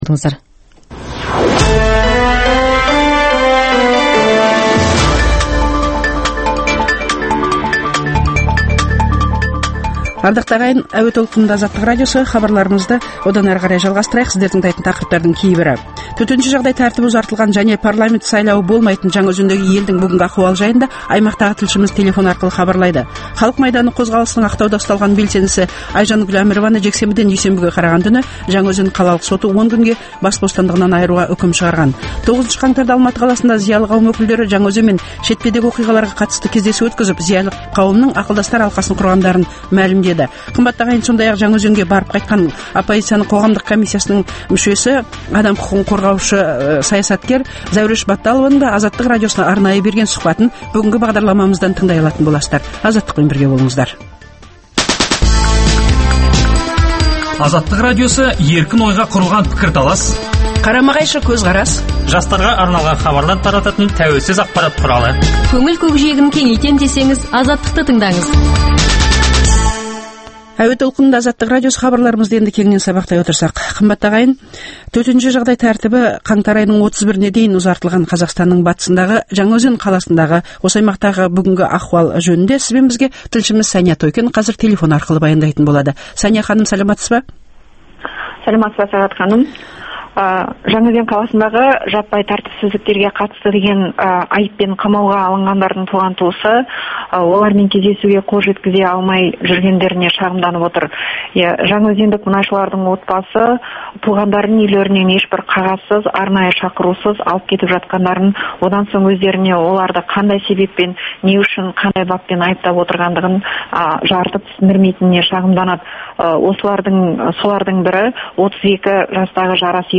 Төтенше жағдай тәртібі ұзартылған және парламент сайлауы болмайтын Жаңаөзендегі елдің бүгінгі ахуалы жай аймақтағы тілшіміз телефон арқылы хабарлайды